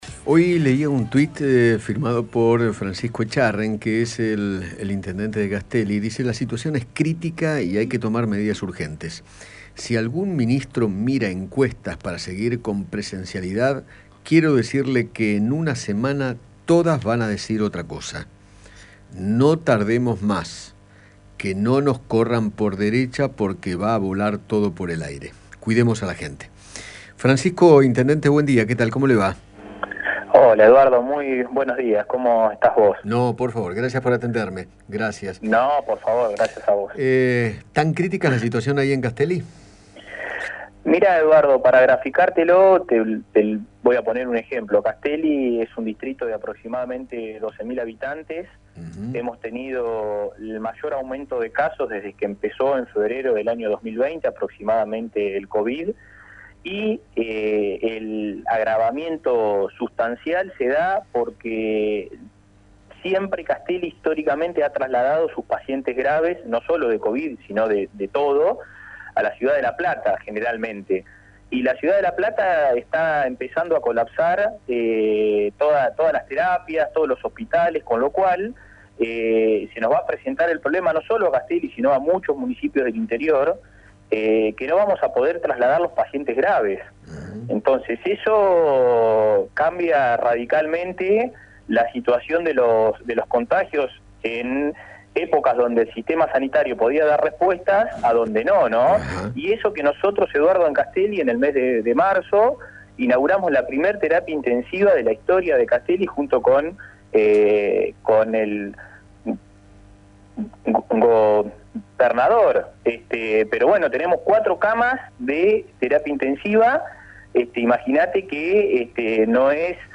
Francisco Echarren, intendente de Castelli, conversó con Eduardo Feinmann acerca de las complicaciones que se empiezan a presentar para derivar a infectados graves a los hospitales de La Plata debido al colapso de las camas UTI.